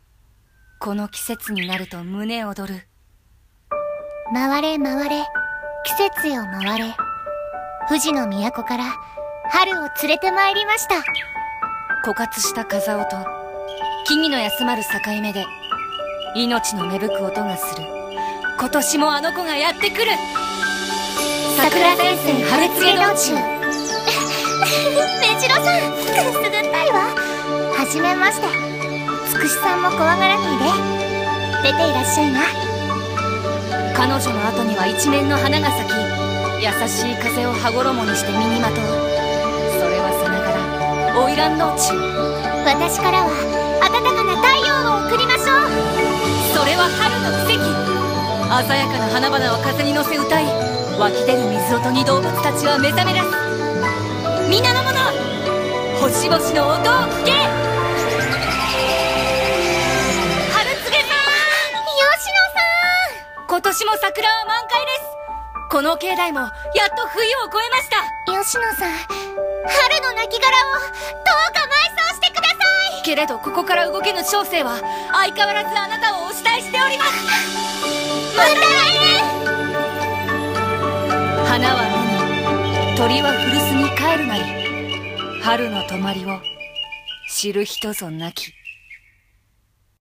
CM風声劇